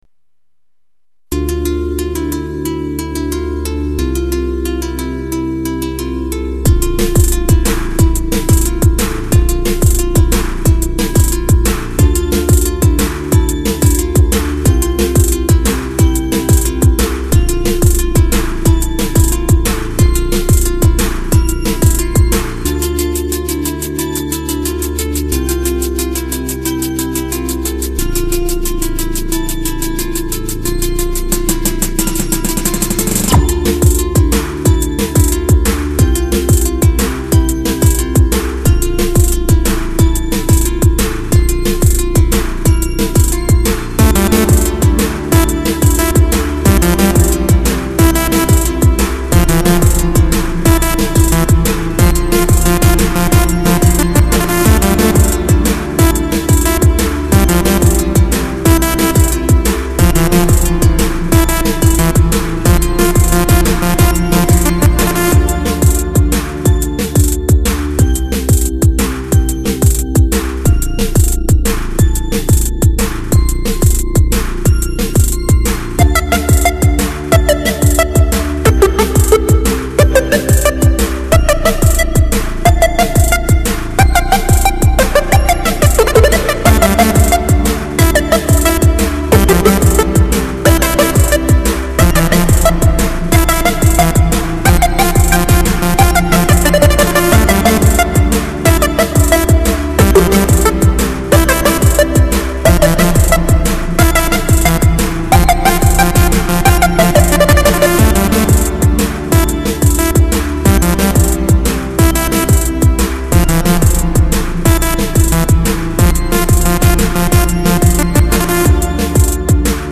elektronnaja_muzika___kazantip__kazantip_2007_.mp3